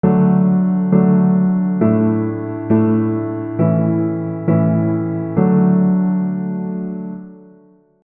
I-Vsus4-V-I
Chords: D - Asus4 - A - D